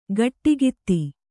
♪ gaṭṭigitti